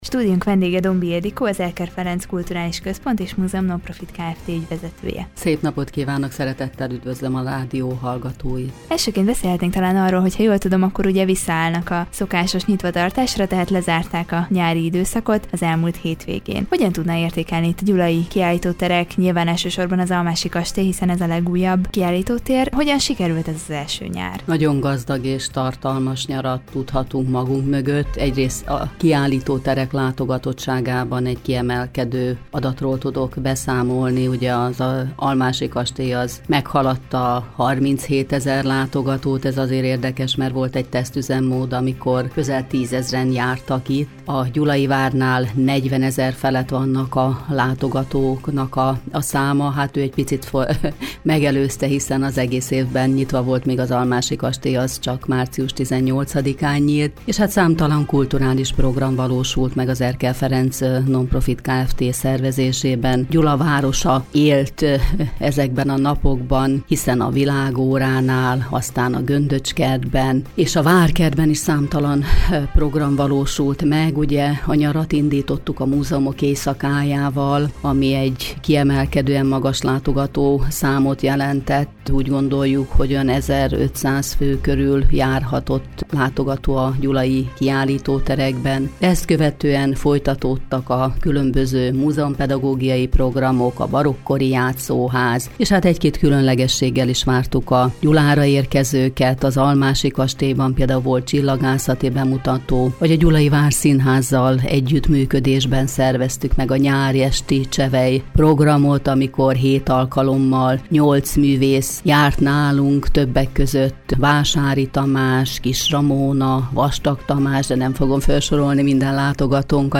Vele beszélgetett tudósítónk a nyári időszakban a kiállítóterek kiemelkedő látogatottságáról valamint a jövőbeni tervekről.